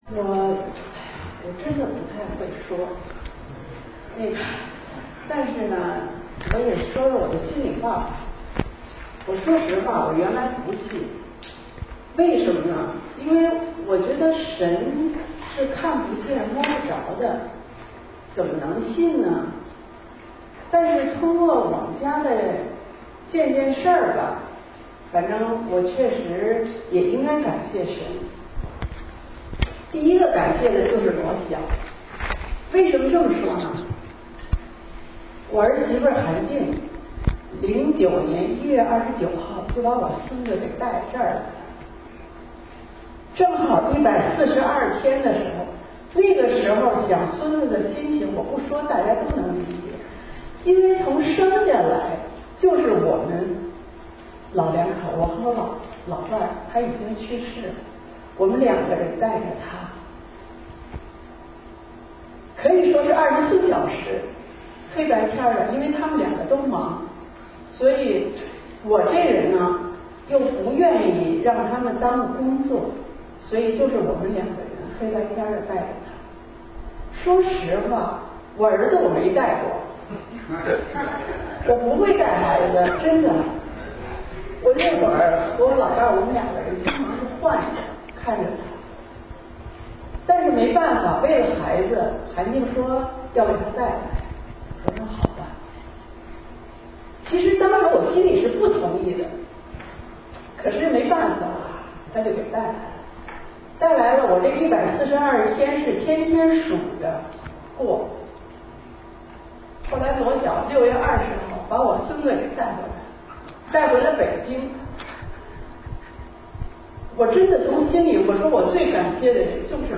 2017年复活节主日洗礼崇拜及分享 – 圣市华人宣道会